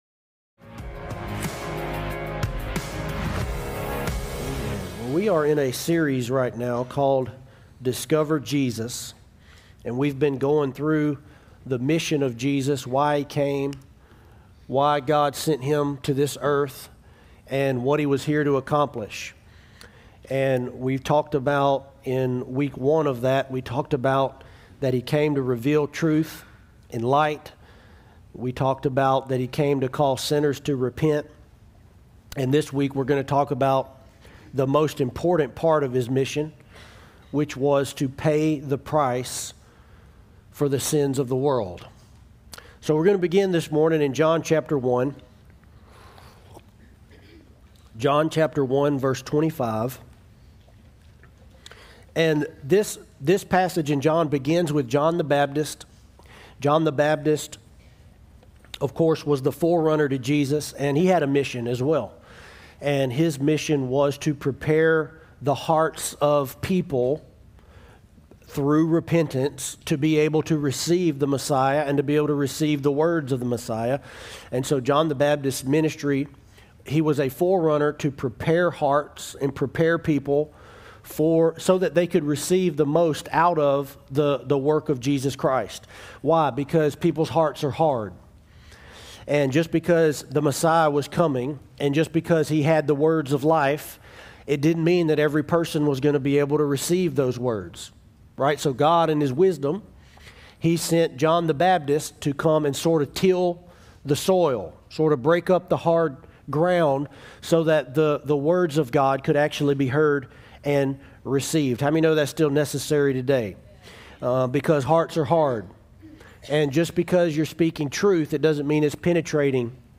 Discover Jesus Sermon Series